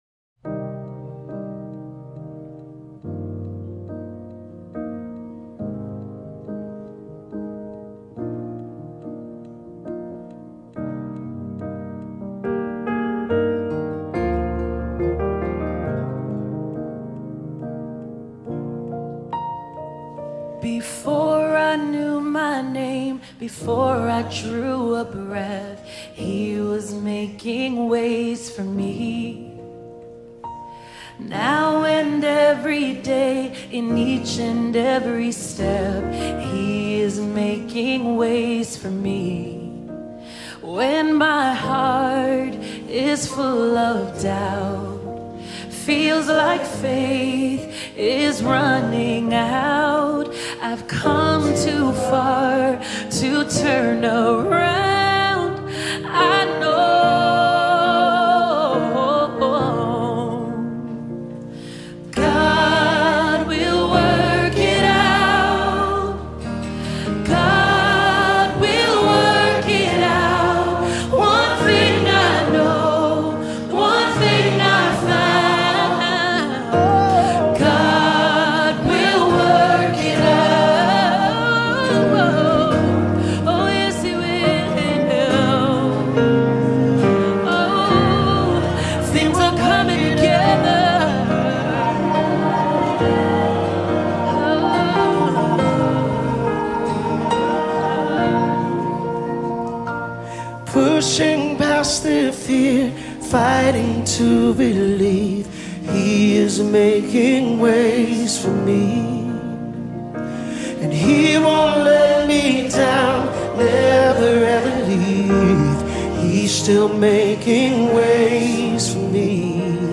contemporary worship music collective
Recorded at a live worship event celebrating Juneteenth.